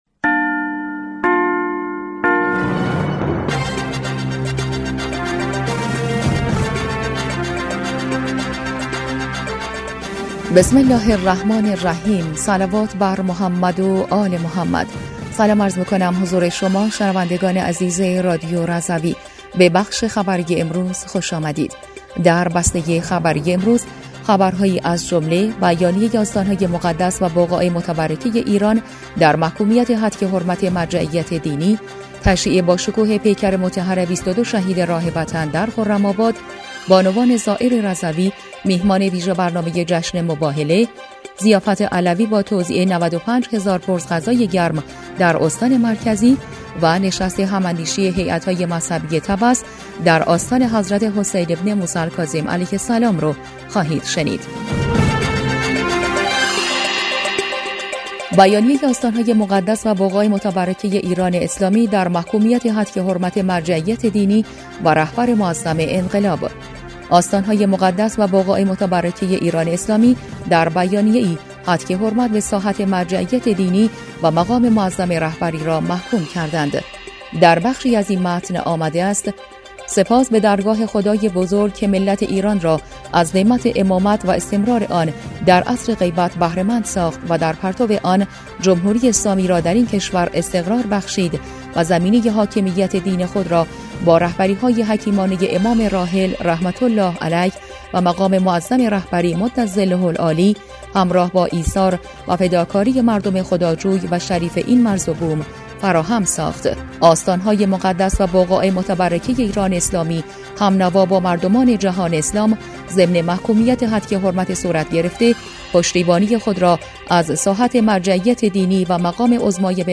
بسته خبری اول تیرماه ۱۴۰۴ رادیو رضوی/